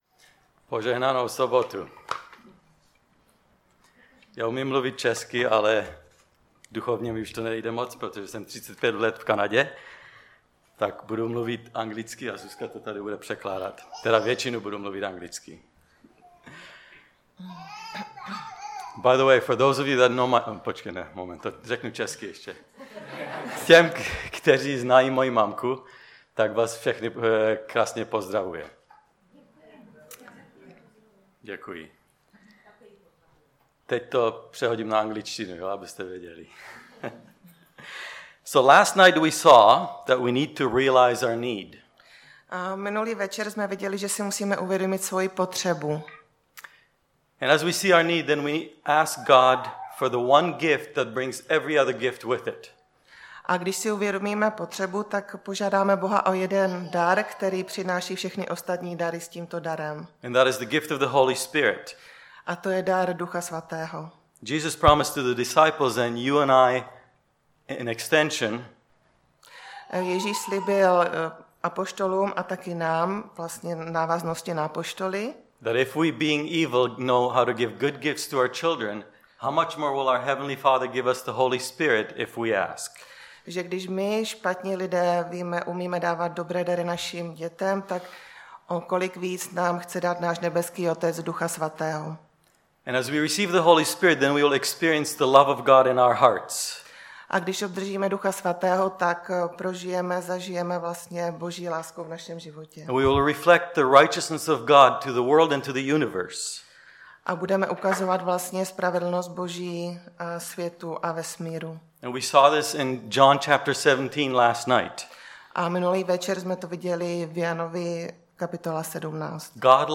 Série: Odvážíš se věřit? Typ Služby: Přednáška Preacher